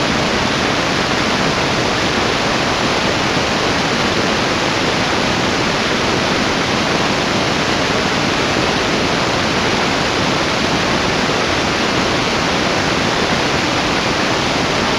Perlin noise with multiple octaves.
The noise above sounds a little like the noise from water when opening the tap fully to fill up a bathtub. The Gaussian mode g adds to that, by making each R instance fluctuate more in amplitude.